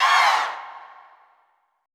Index of /90_sSampleCDs/Best Service - Extended Classical Choir/Partition I/AHH FALLS
AHH HI FST-L.wav